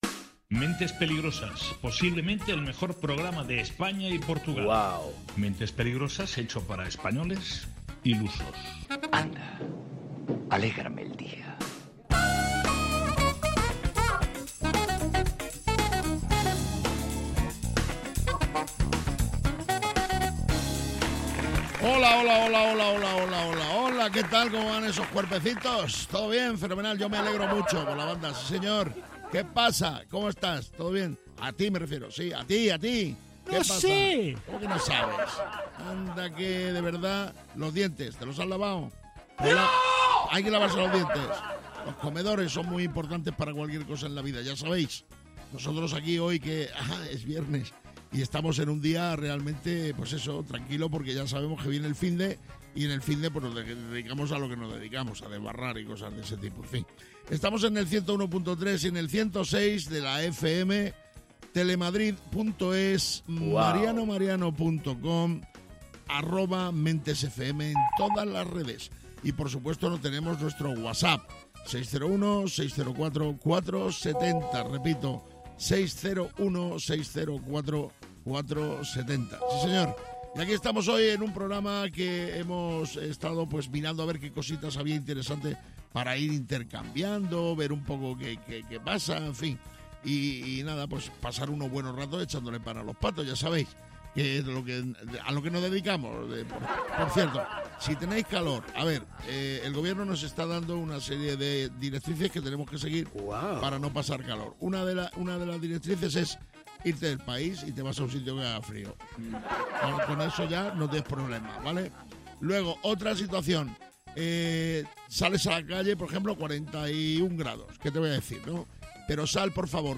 Mentes Peligrosas es humor, y quizás os preguntaréis, ¿y de qué tipo de humor es?